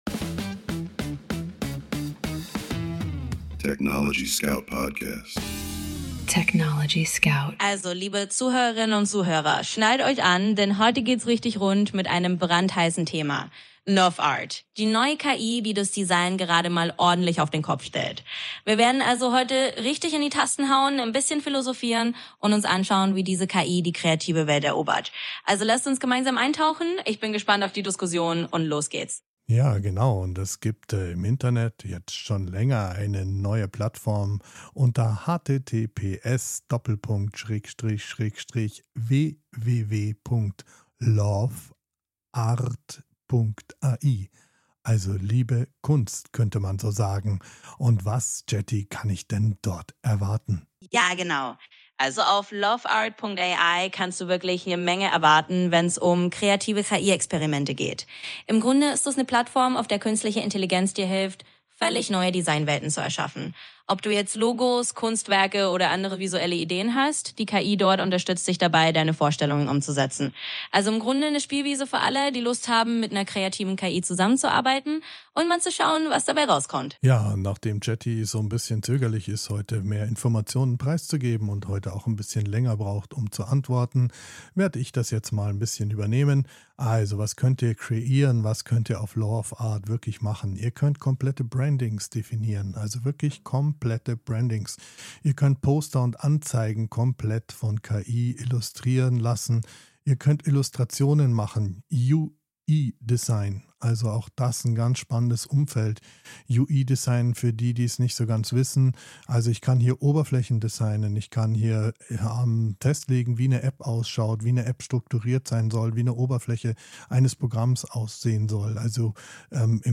"Live aus dem Studio des TechnologieScout – wenn KI auf Kopf & Herz trifft